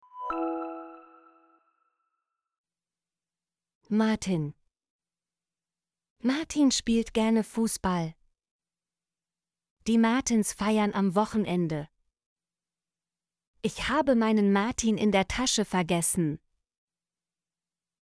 martin - Example Sentences & Pronunciation, German Frequency List